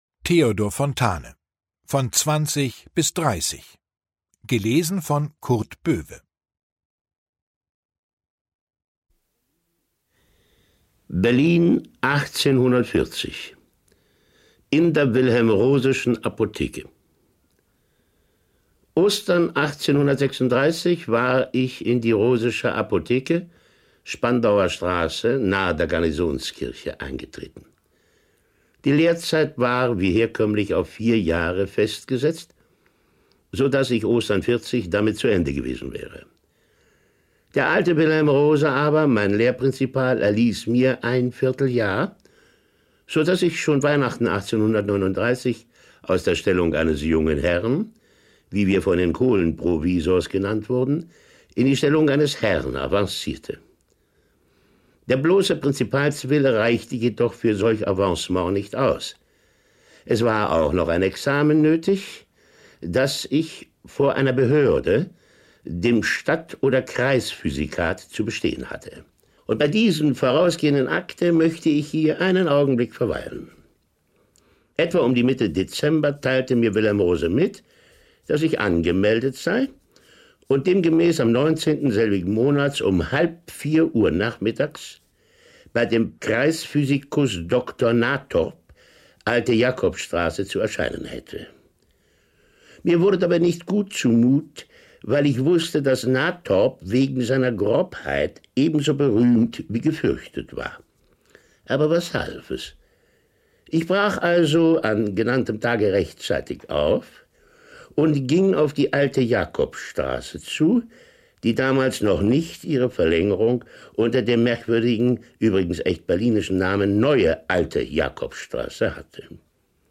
Kurt Böwe (Sprecher)
2021 | Gekürzte Lesung
Kurt Böwe liest Fontanes Erinnerungen einfühlsam und authentisch.
»Jedes Wort ist an seinem Platz. Kein Stolpern, kein Pathos, keine Verkrampftheit.« Neue Zürcher Zeitung